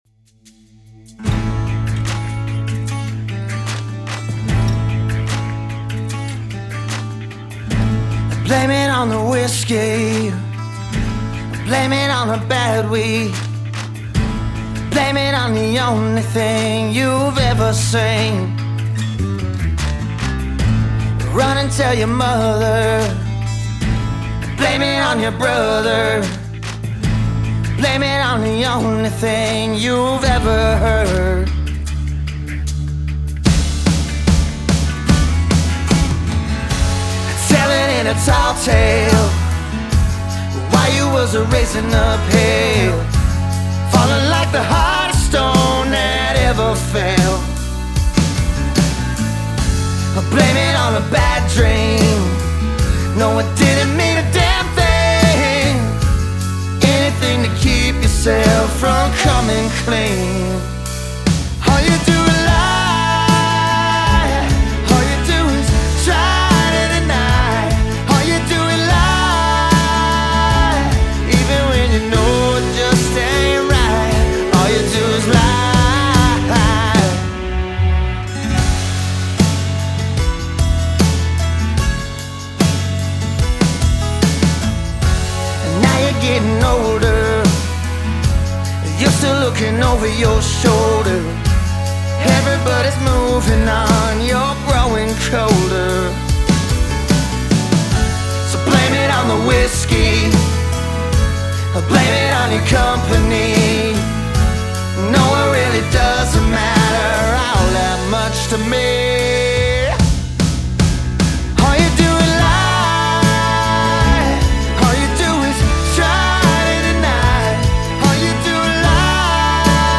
Den/die Shaker darf man aber gut hören.
das ist die Akustik-Version!?